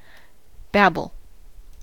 babble: Wikimedia Commons US English Pronunciations
En-us-babble.WAV